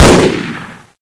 hksShoot1.ogg